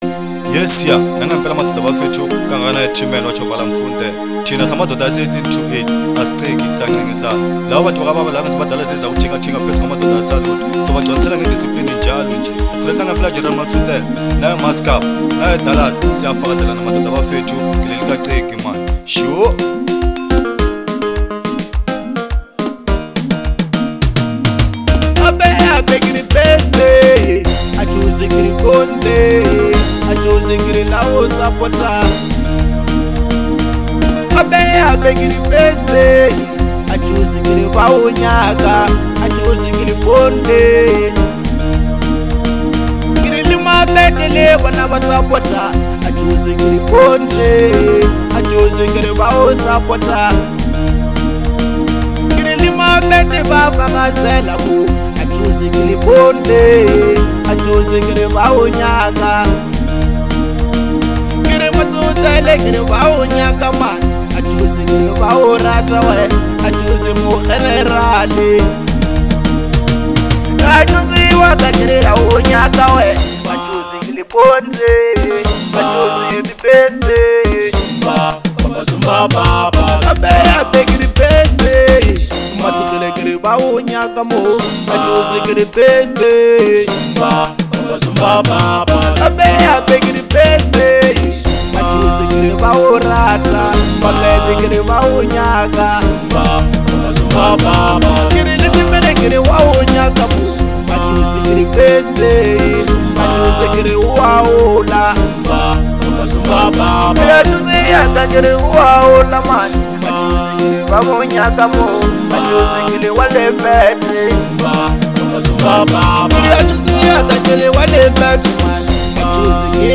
06:26 Genre : Bolo House Size